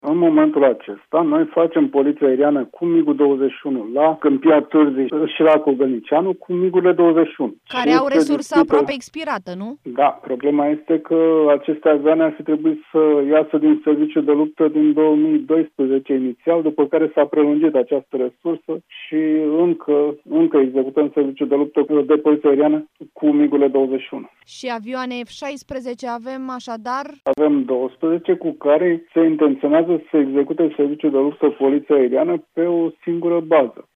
Generalul Ștefan Dănilă mai spune în interviul acordat Europa FM, că cele 12 avioane F 16 vor putea asigura doar parțial Poliția Aeriană a României.